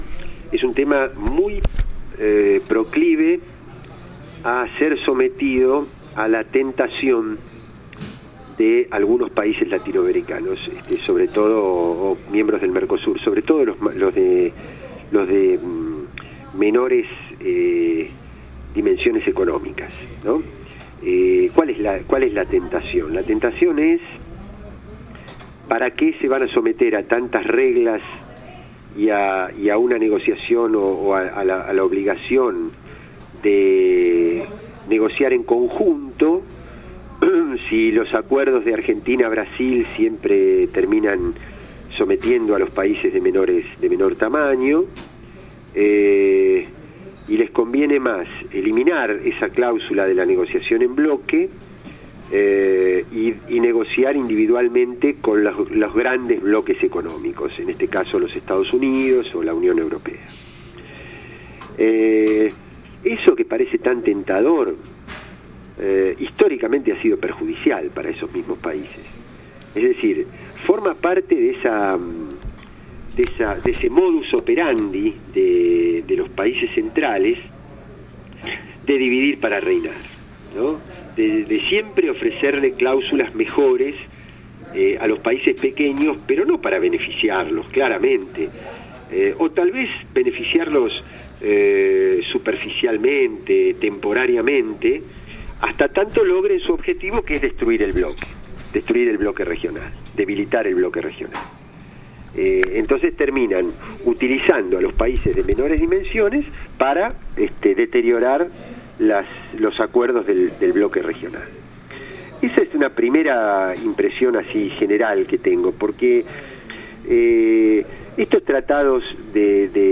(+Audio) Entrevista al Parlamentario Carlos Raimundi
El Parlamentario argentino Carlos Raimundi, fue entrevistado por Radio PARLASUR sobre sus opiniones referentes a las perspectivas del Parlamento del MERCOSUR y el posible acuerdo comercial entre el MERCOSUR y la Unión Europea.